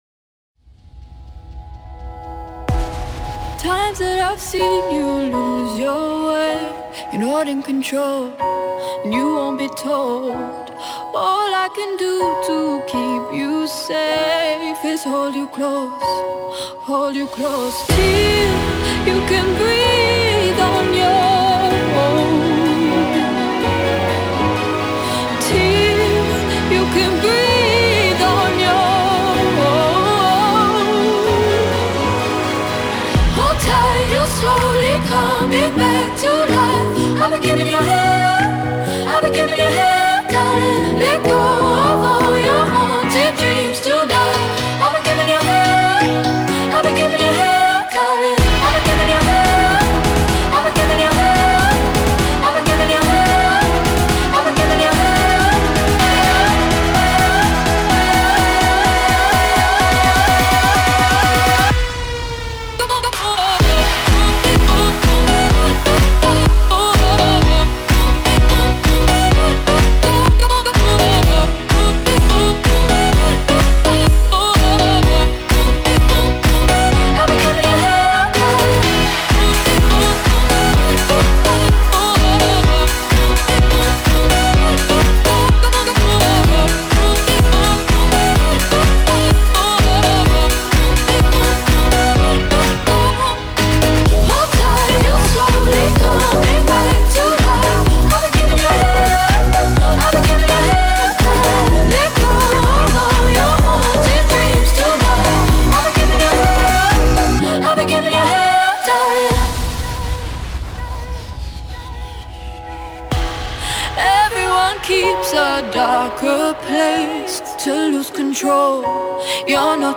Show Radio live DJ Portugal CLubbing